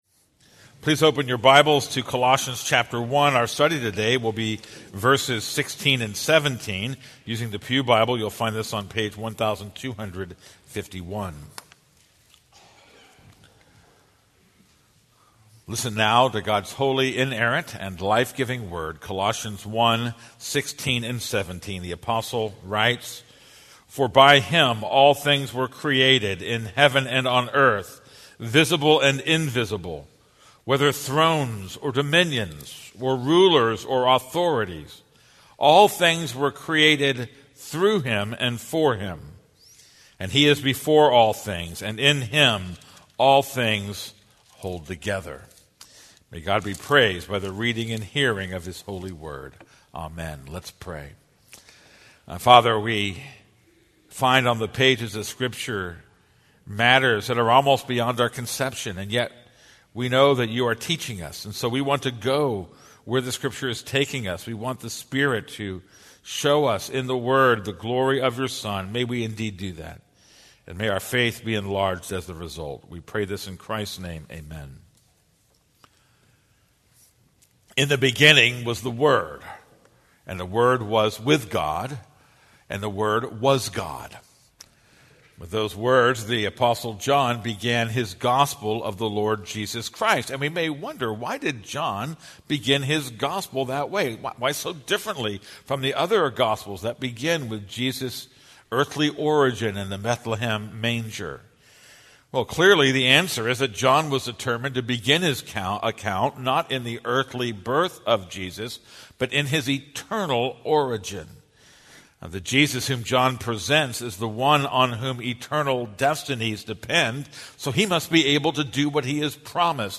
This is a sermon on Colossians 1:16-17.